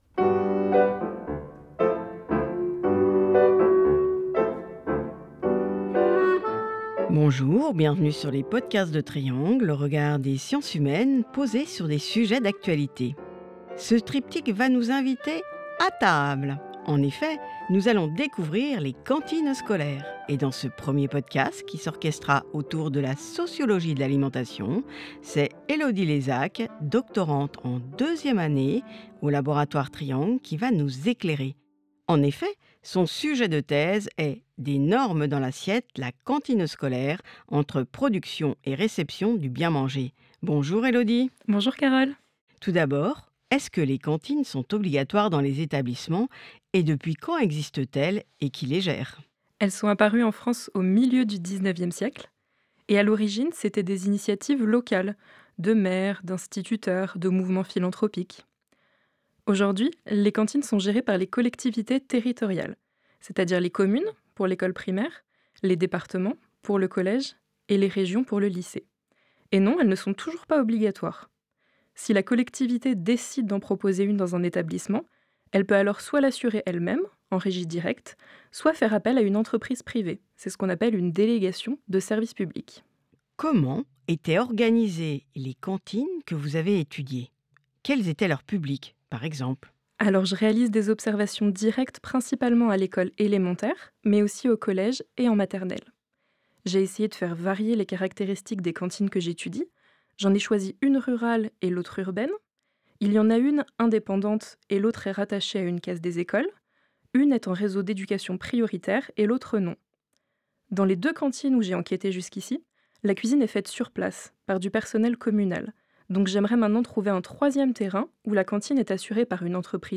>> Lire la retranscription des propos de l’interview :